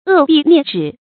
扼臂啮指 è bì niè zhǐ
扼臂啮指发音